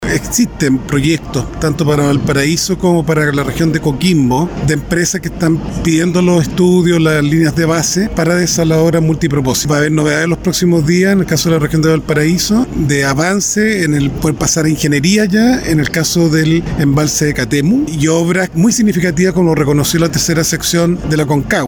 Posterior a la cuenta pública y consultado por Radio Bío Bío, el ministro de Agricultura, Esteban Valenzuela, adelantó importantes proyectos en materia hídrica para el cuidado y reutilización de aguas.